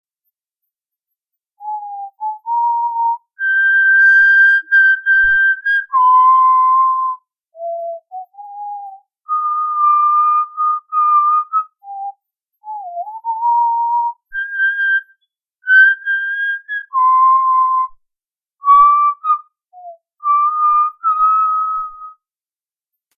这首曲只能用口哨来回忆了！
此曲口哨 -- 重新修录 ， 如还是很小声， 请告知。